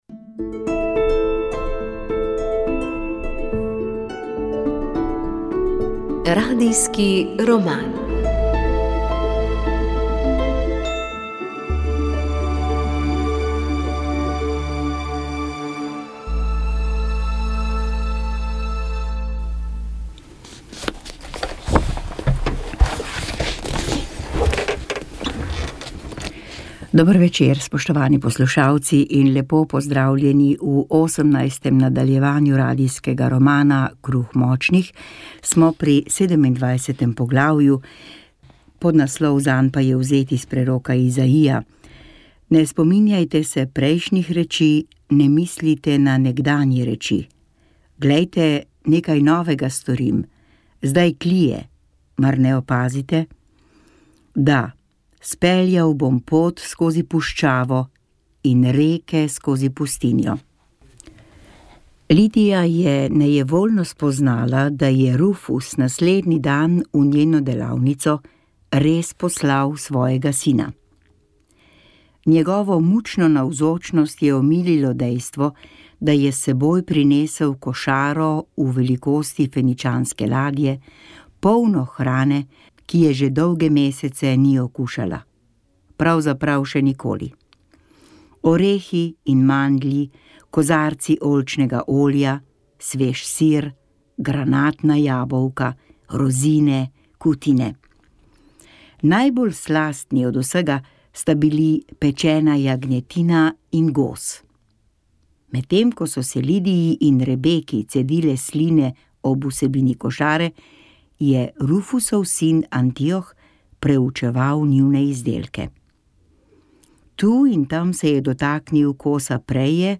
Radio Ognjišče knjiga Radijski roman VEČ ...